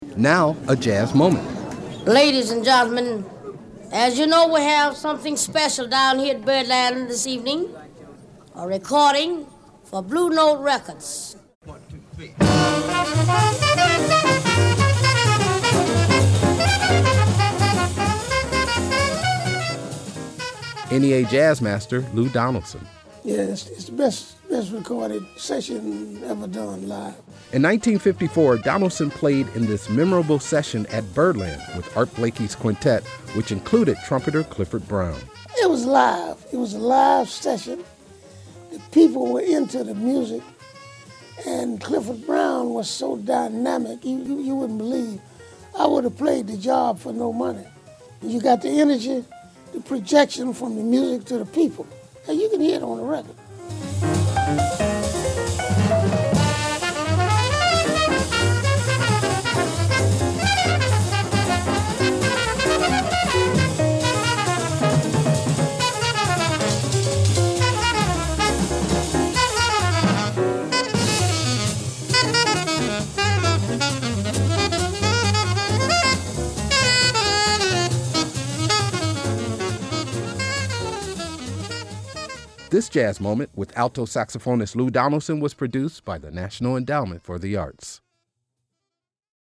Excerpt of "Quicksilver" composed by Horace Silver from the album, A Night at Birdland, used courtesy of EMI Capitol and by permission of Ecaroh Music (ASCAP).